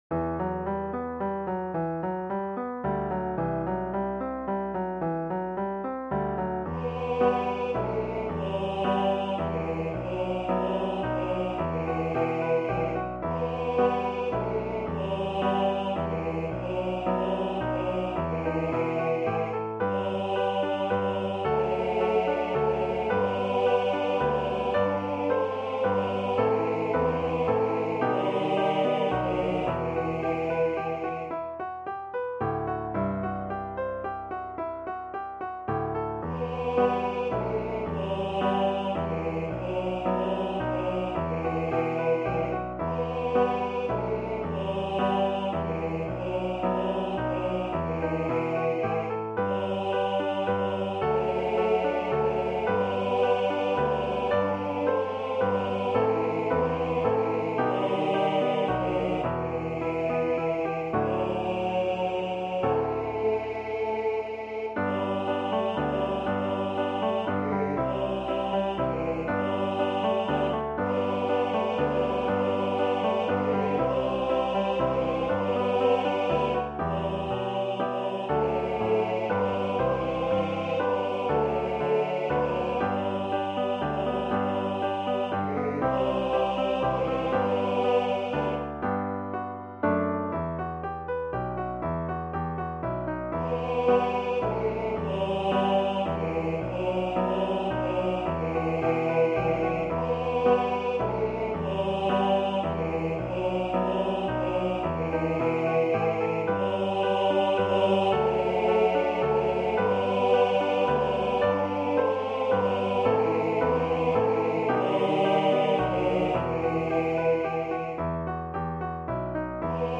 Voicing/Instrumentation: TBB We also have other 15 arrangements of " We Three Kings of Orient Are ".